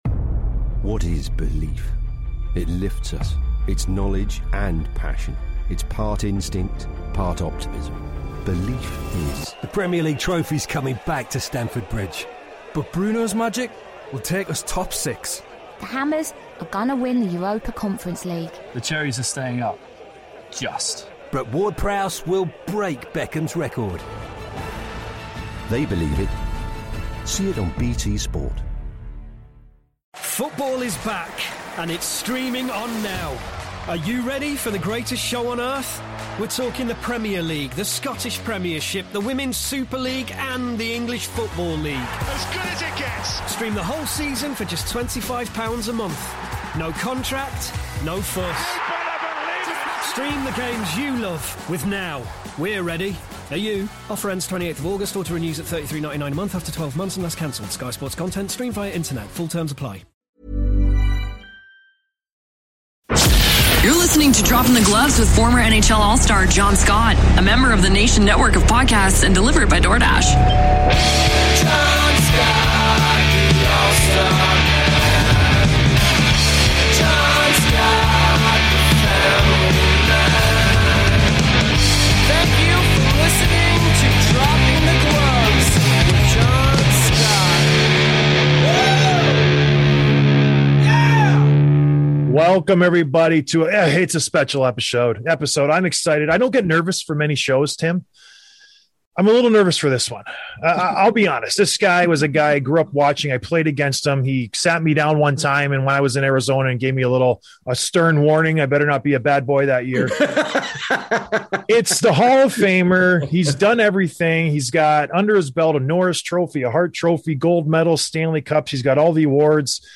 Interview with Chris Pronger (Re-release)